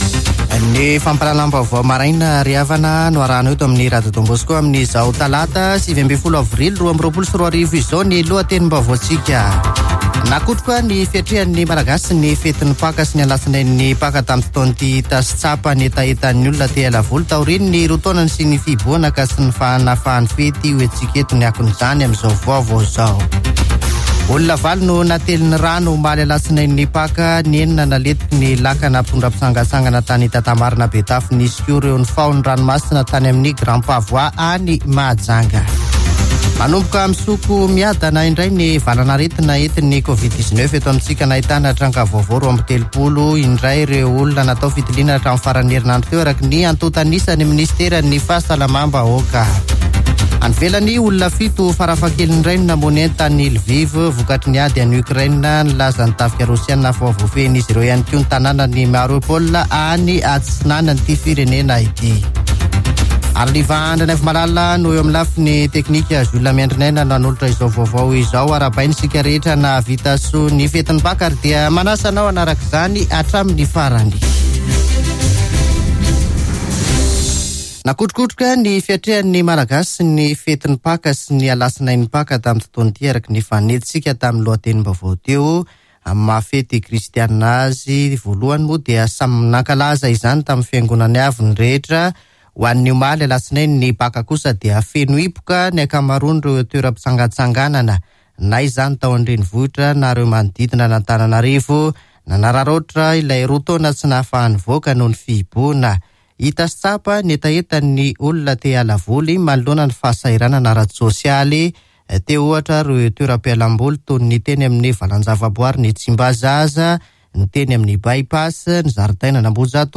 [Vaovao maraina] Talata 19 aprily 2022